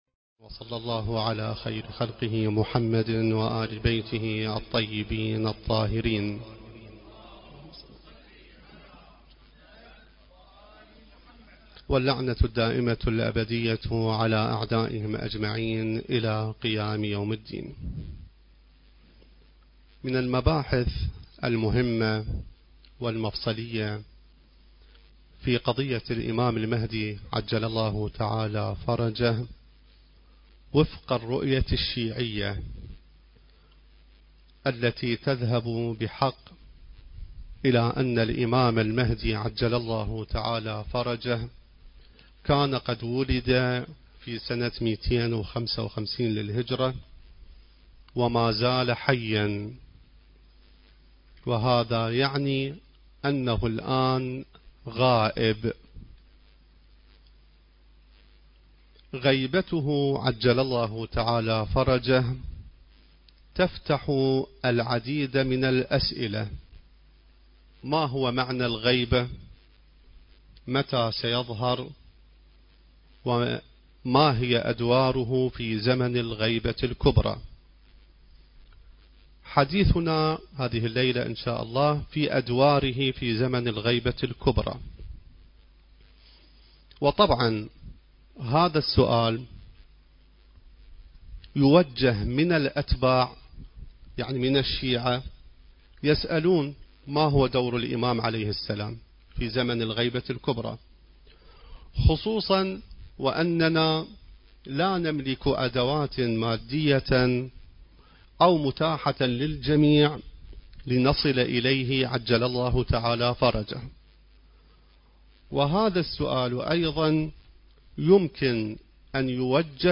ندوة عين الحياة